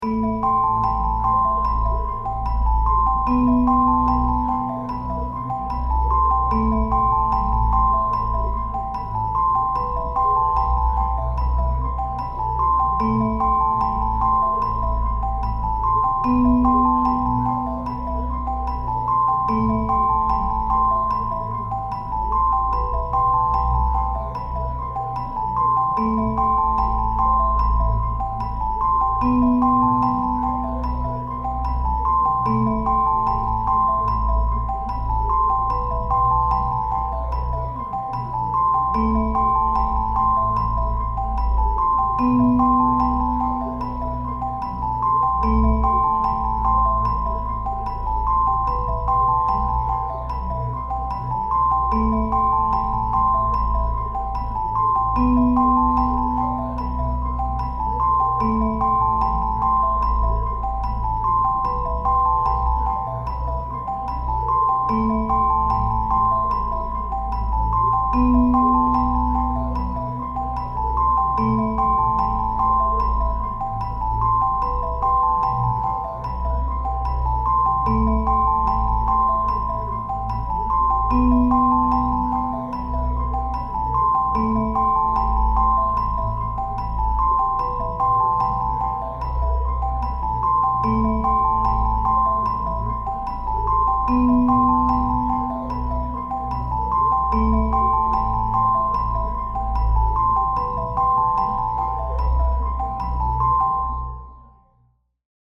ミステリアスでホラー味のある不気味で怖いBGMです。